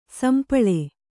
♪ sampaḷe